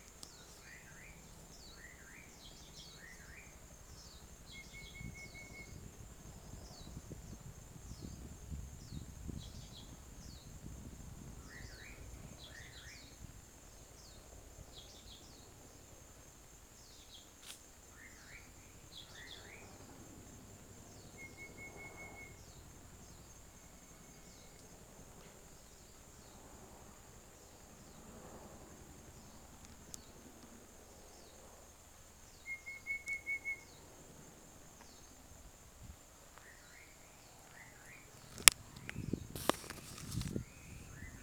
第二個聲音太慘了，只有破碎的噪音
第二個是小彎嘴沒錯..還夾雜黑枕藍鶲的叫聲